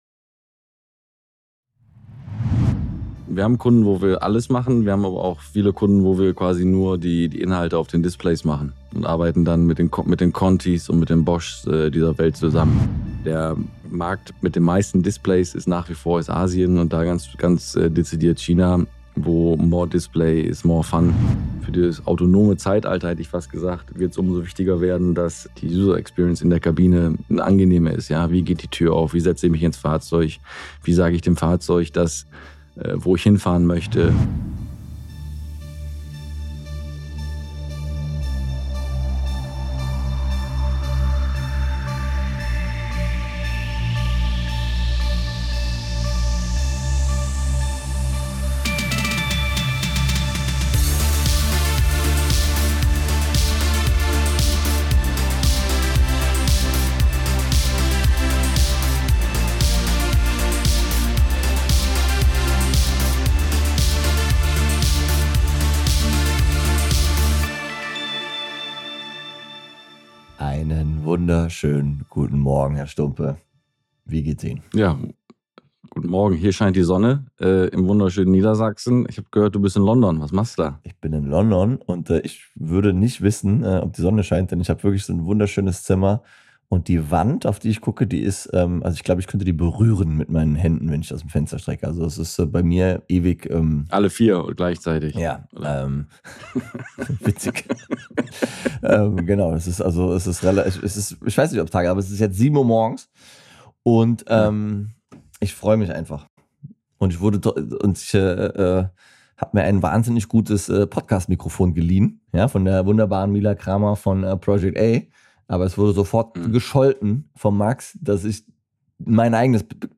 aus London und Berlin